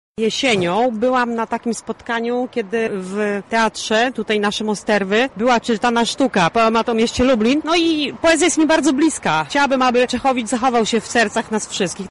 To wszystko w ramach spaceru szlakiem poematu Józefa Czechowicza.
Uczestnik